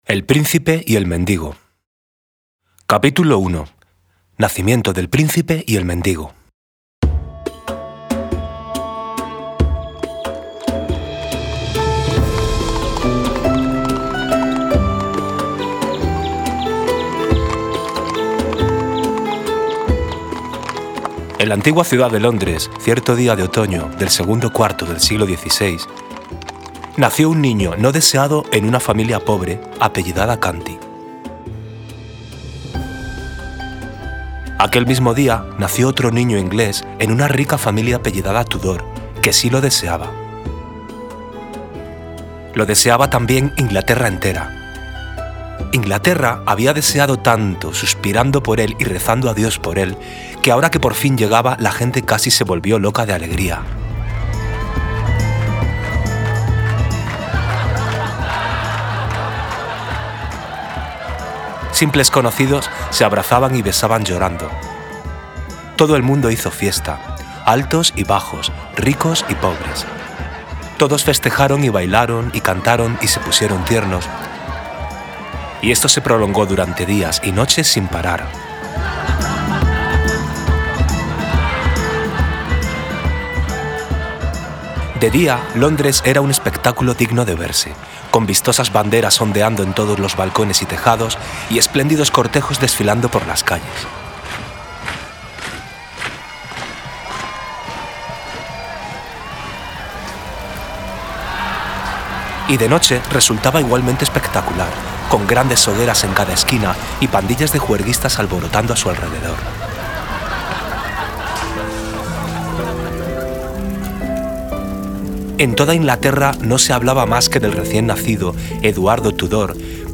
Lectura inicial. El príncipe y el mendigo